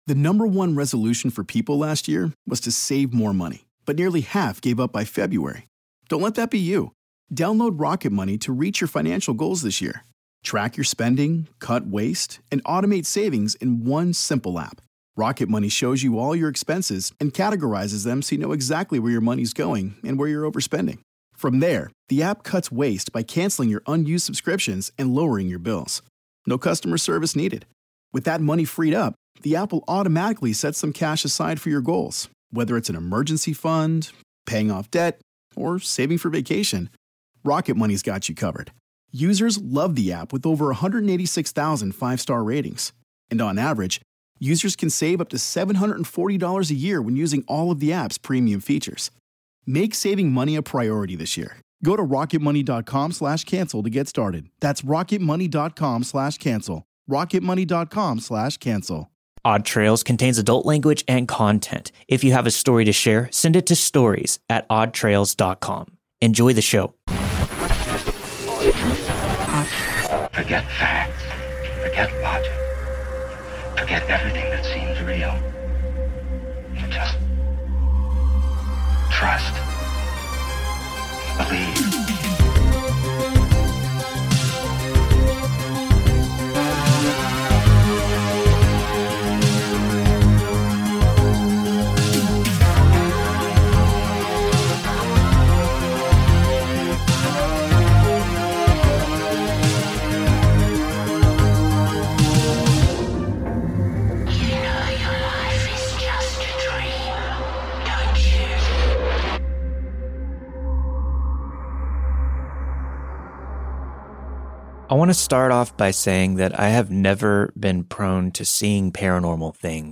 All the stories you've heard this week were narrated and produced with the permission of their respective authors.